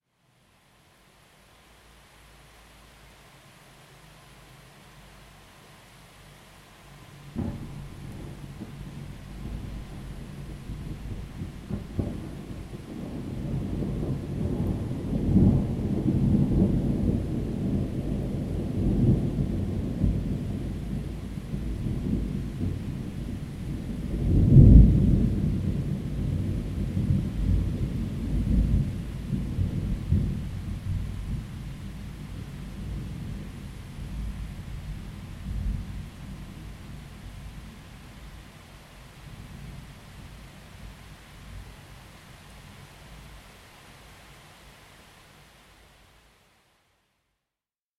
高级音响效果雷声和雨声2
描述：雷声和雨声的FX声景。背景是蟋蟀。用H2next录制的。